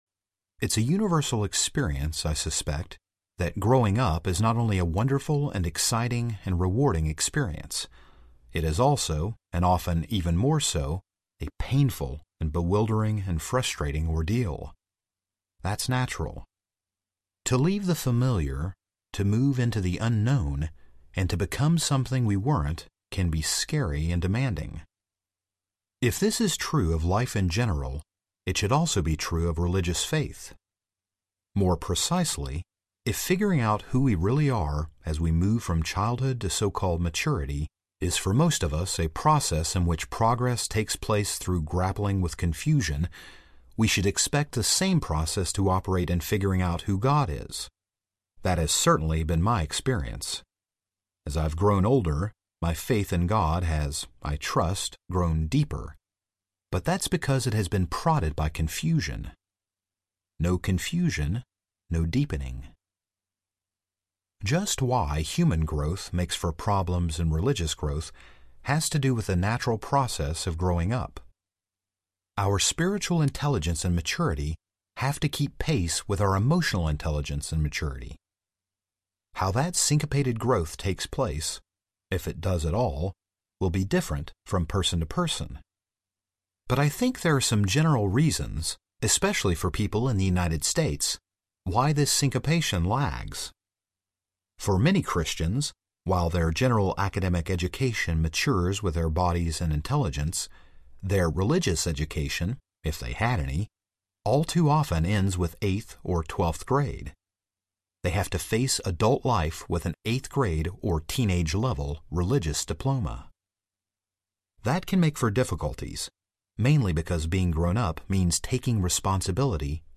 Without Buddha I Could Not Be a Christian Audiobook
Narrator
11.6 Hrs. – Unabridged